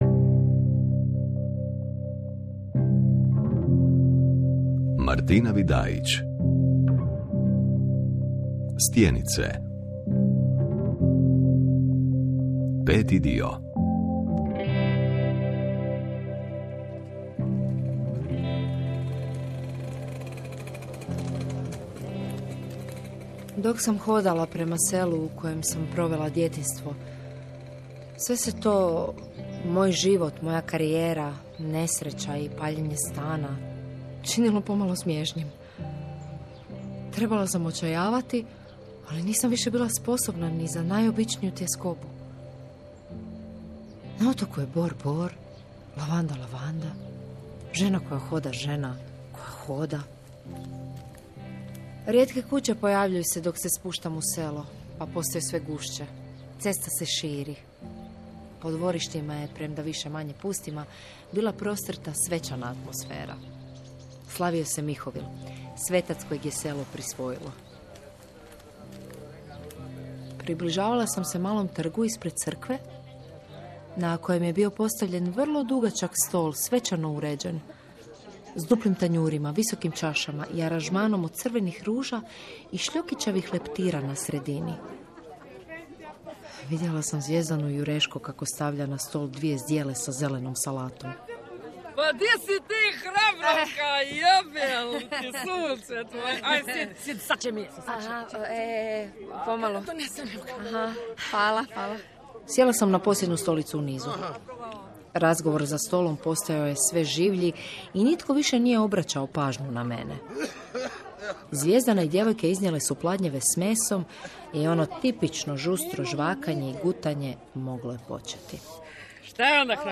Suvremena prozna produkcija hrvatskih autora narativno-igrane dramaturgije, u formi serijala.